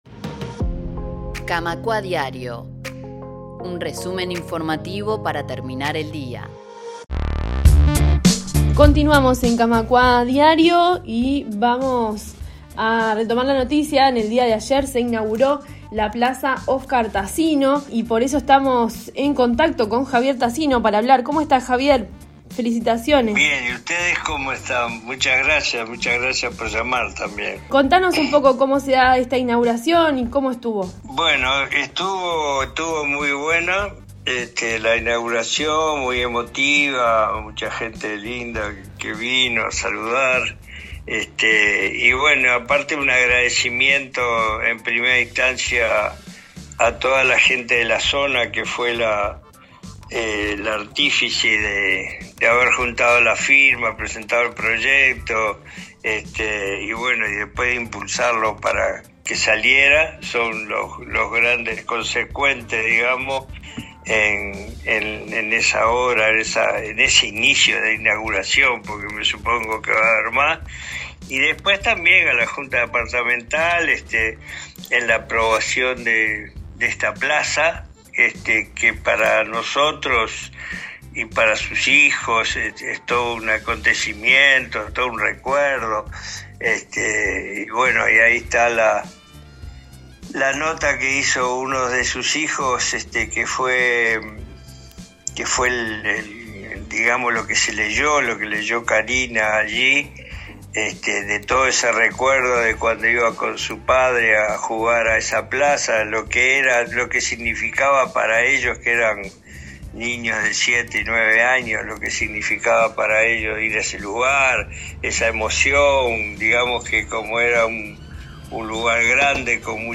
Coloquio sobre la reforma laboral en Argentina